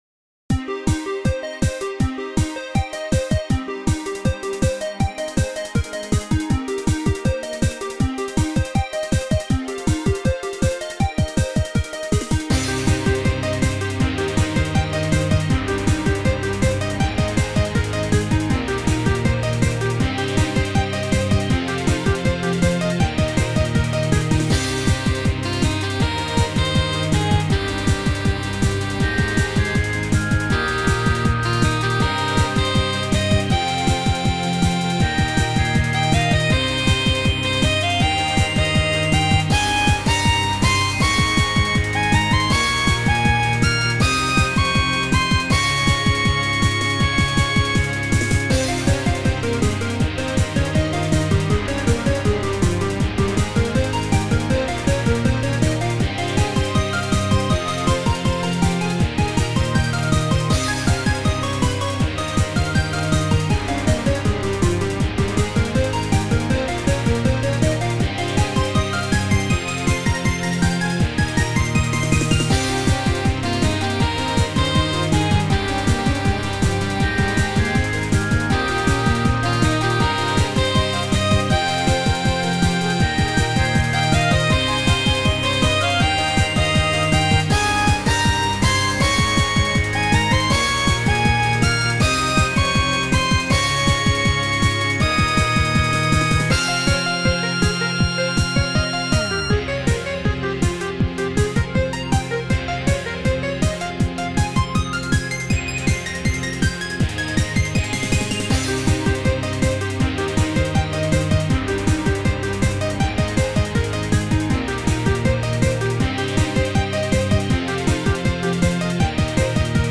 ジャンル のんびりPop(基本全部PopにしちゃうからもうPopって書くのやめたい)
自然に囲まれた道を駆けて往く感じの曲です